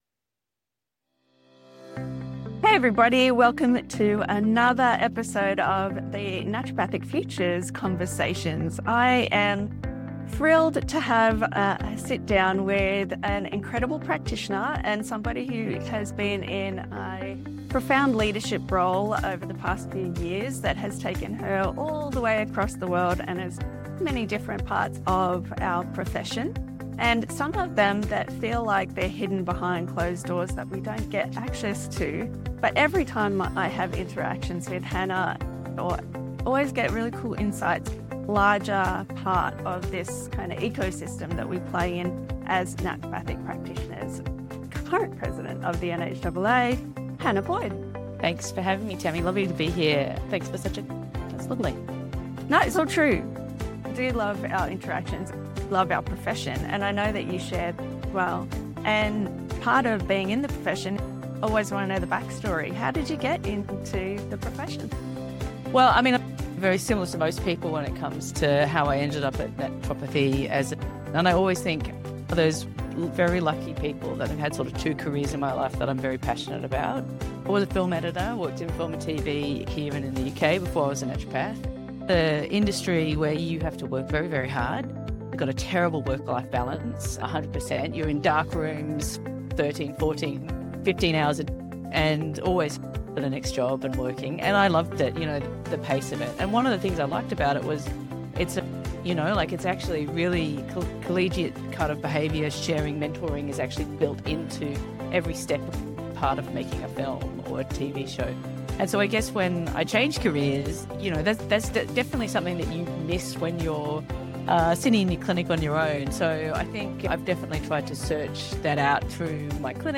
This conversation is both visionary and practical, reminding us how our diverse backgrounds enrich our profession and how we can co-create a future that keeps naturopathic care accessible, vibrant, and valued worldwide.